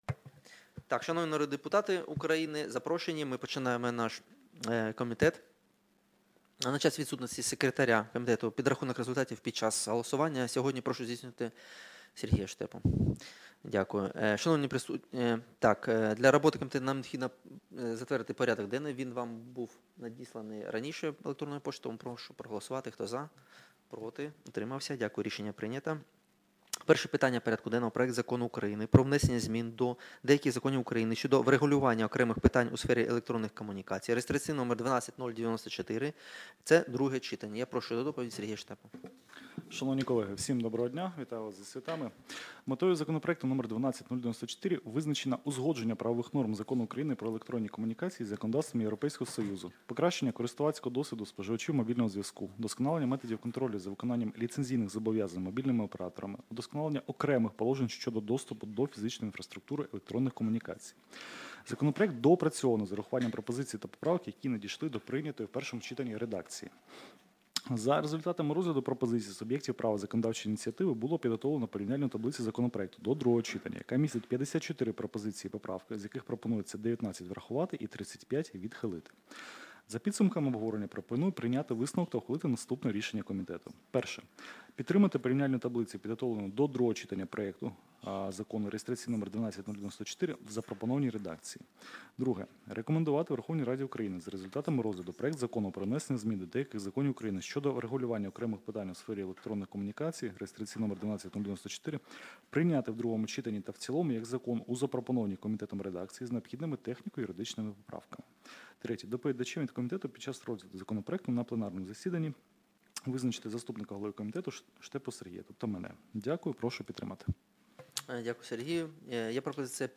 Аудіозапис засідання Комітету від 15.07.2025